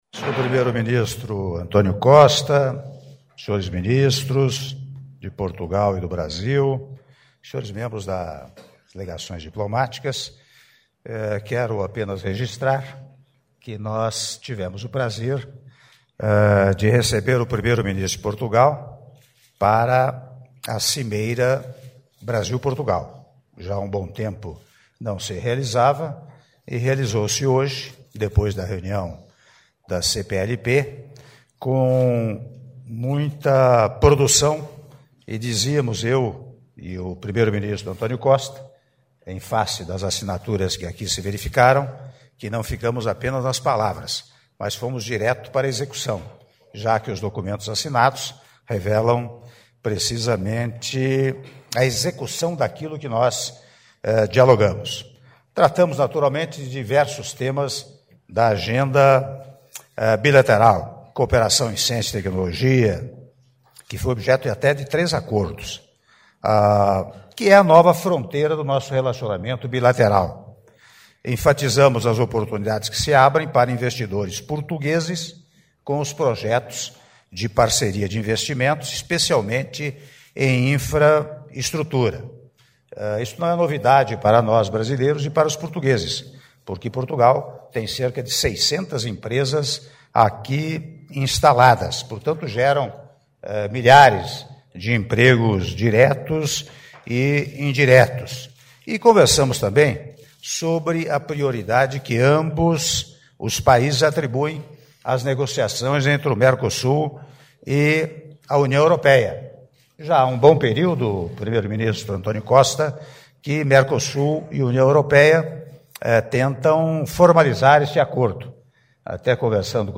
Áudio da declaração à imprensa do Presidente da República, Michel Temer, após reunião de trabalho com o Primeiro-Ministro de Portugal, António Costa - Brasília/DF (04min02s)